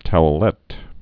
(touə-lĕt)